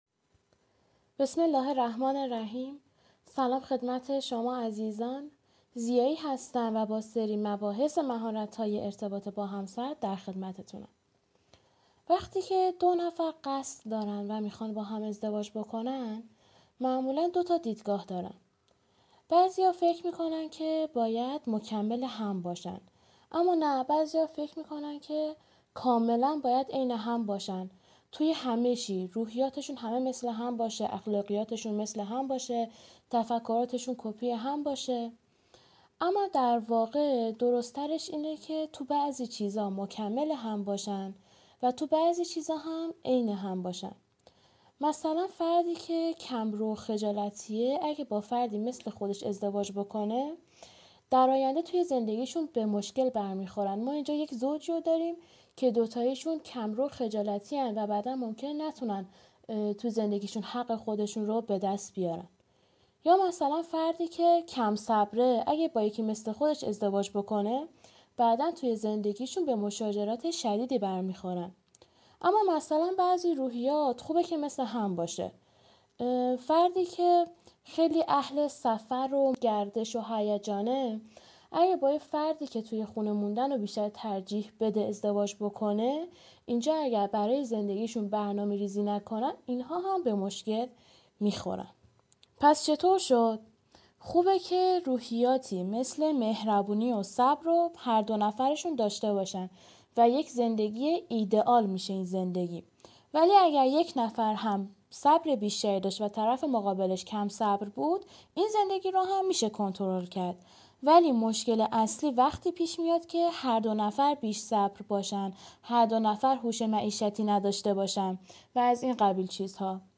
مدرس